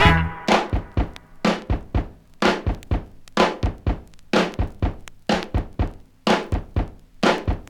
• 125 Bpm High Quality Disco Breakbeat Sample E Key.wav
Free breakbeat sample - kick tuned to the E note.
125-bpm-high-quality-disco-breakbeat-sample-e-key-Kcp.wav